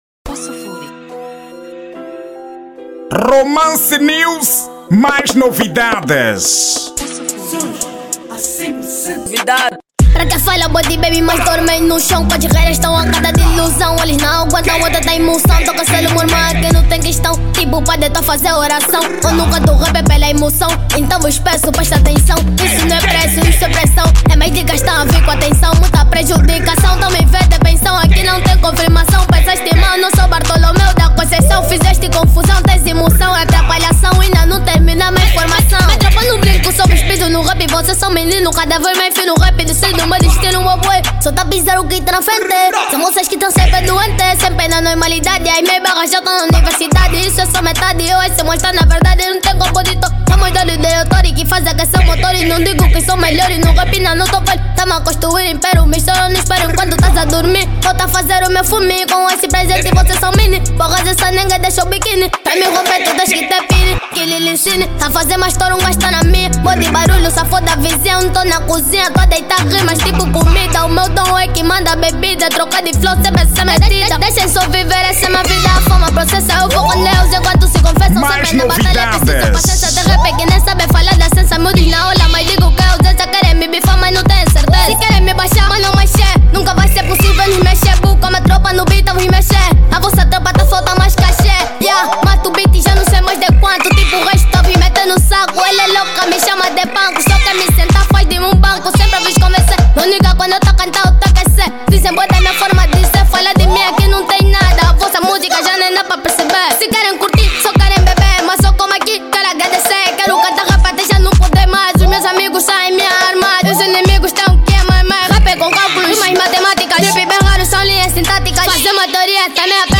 Estilo: Rap Duro